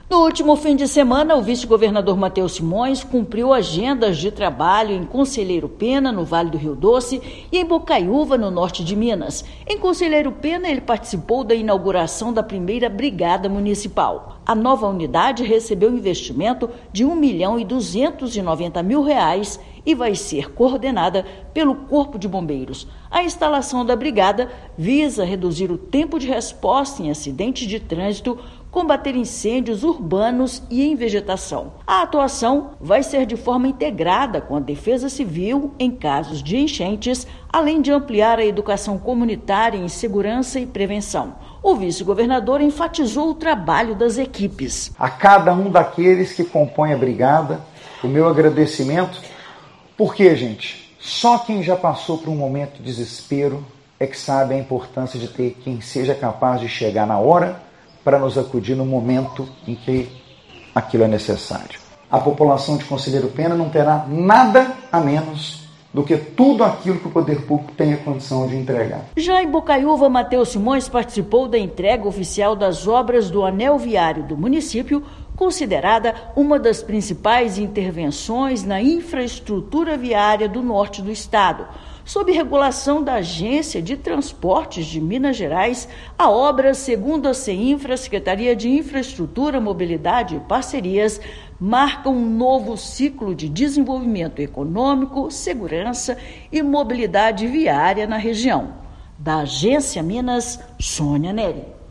O vice-governador participou da inauguração da brigada e da entrega das melhorias do trecho concedido da BR-135. Ouça matéria de rádio.